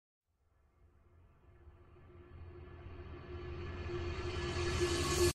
Riser Sound Effect Sound Effects Free Download
Riser - Sound Effect